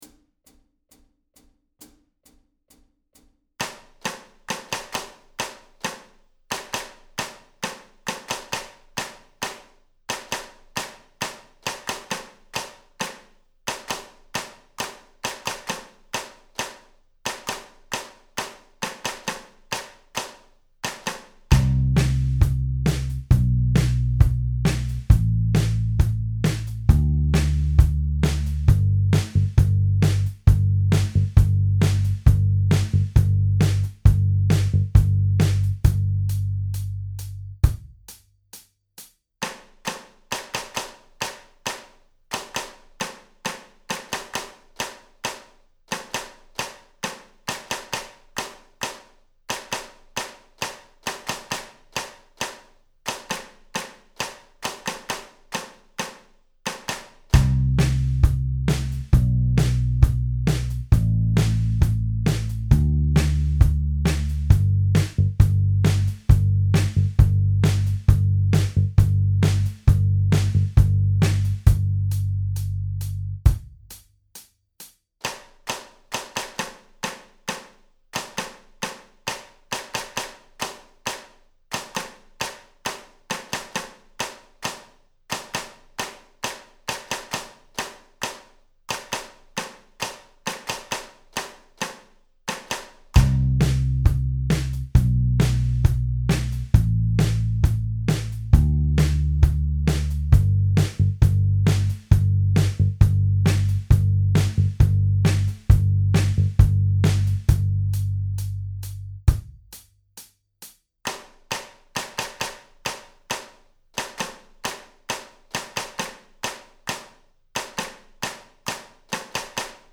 Audio Practice Tracks
Each practice track has a 2 measure count-in, and then the clapping percussion begins.
Moderate-Fast Tempo (134bpm) - download, or press the play button below to stream: